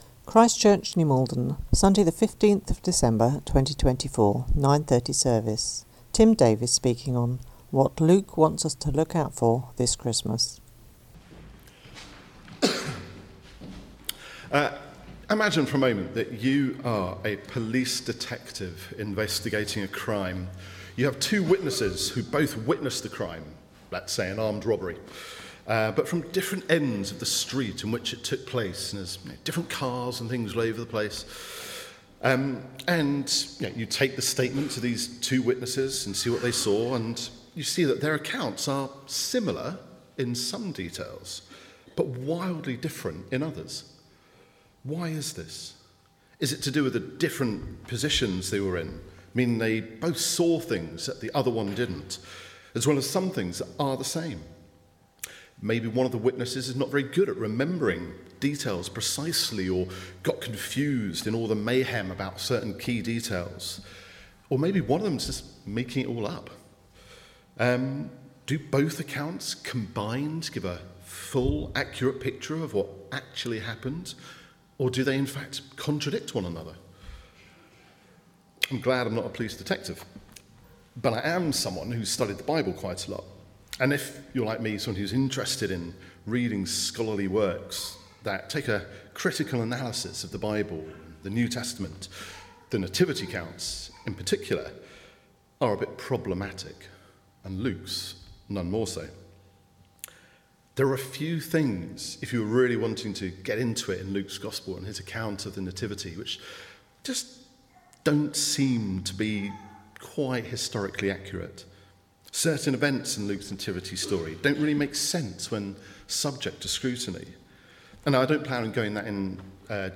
These are our Sunday sermons from the wide variety of services that take place every week.